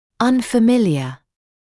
[ˌʌnfə’mɪlɪə][ˌанфэ’милиэ]незнакомый, неизвестный; непривычный